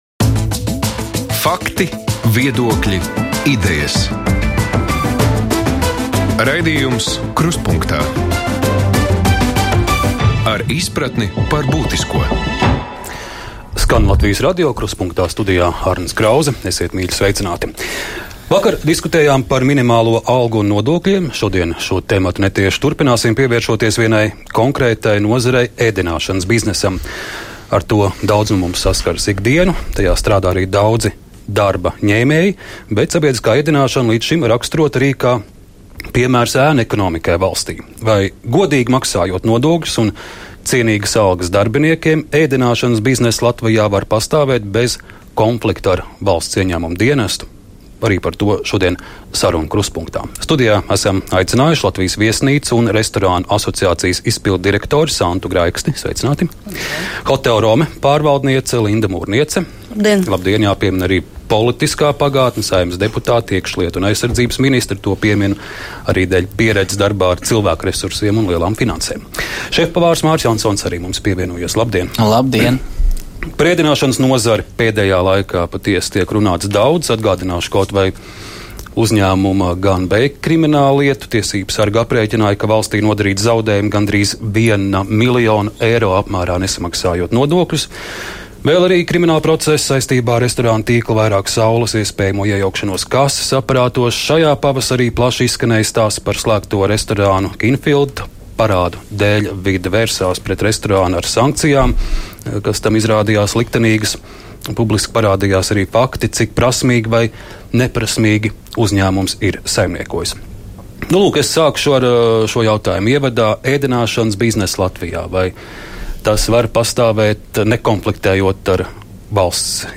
Diskusija: Vai iespējams iznākt no pelēkās zonas sabiedriskās ēdināšanas jomā?